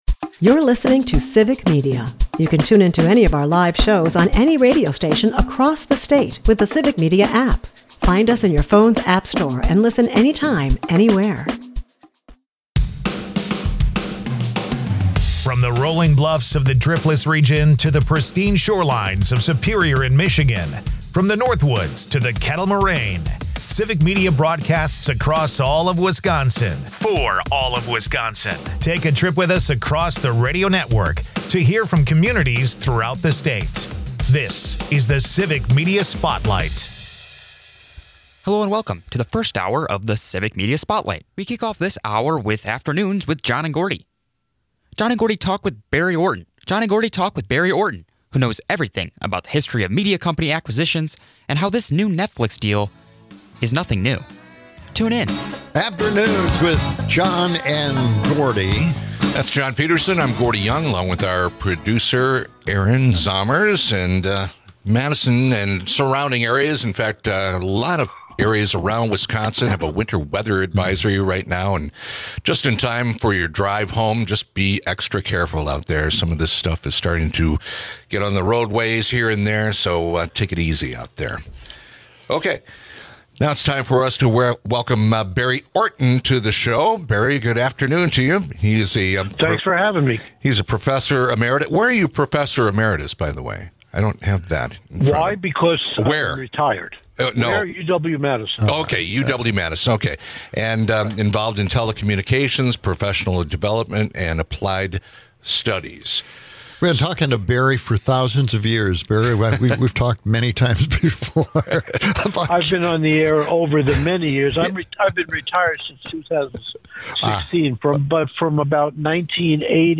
Friends, coworkers, and listeners celebrate her incredible impact on
Friends, coworkers, and listeners celebrate her incredible impact on the station, sharing stories, laughs, and heartfelt thanks.